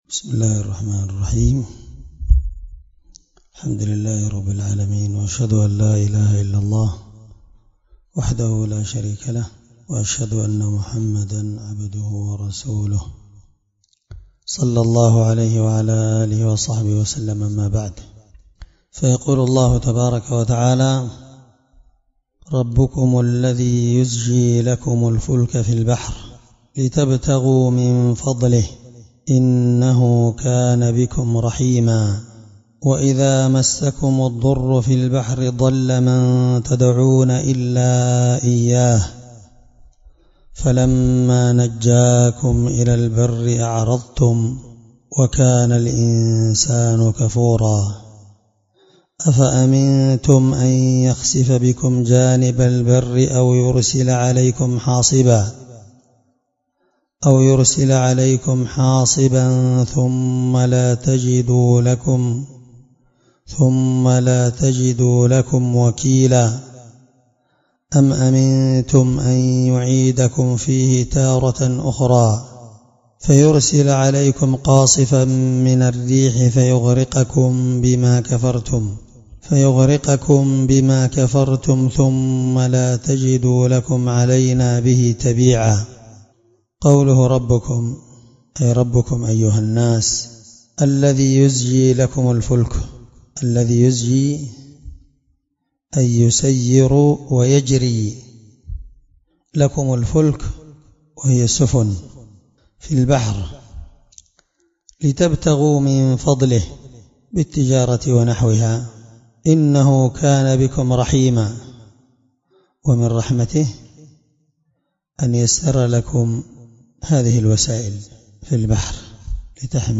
الدرس21 تفسير آية (66-69) من سورة الإسراء
17سورة الإسراء مع قراءة لتفسير السعدي